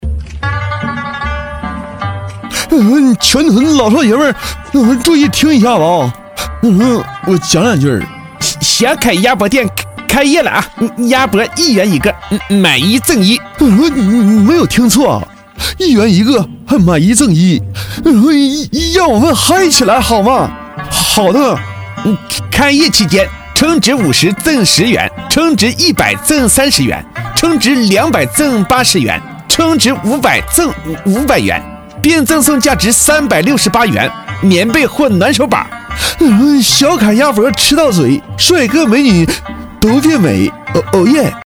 【模仿】赵四、刘能